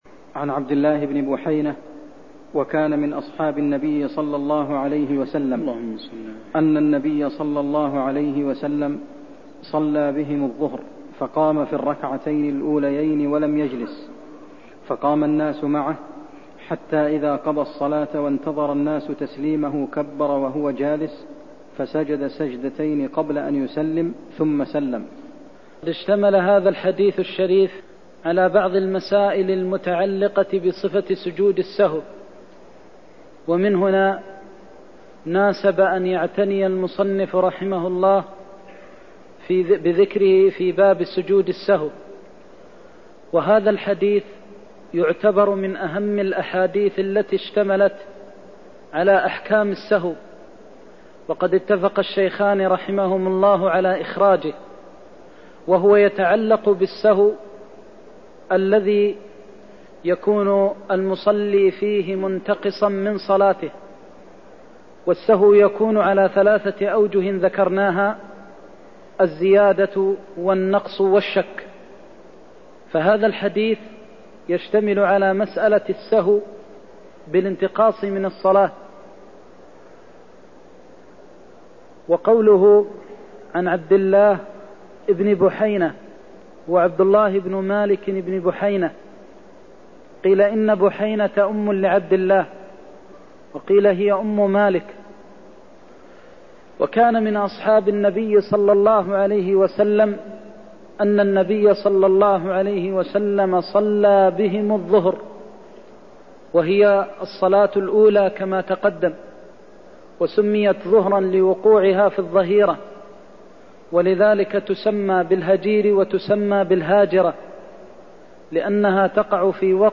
المكان: المسجد النبوي الشيخ: فضيلة الشيخ د. محمد بن محمد المختار فضيلة الشيخ د. محمد بن محمد المختار صفة سجود السهو (101)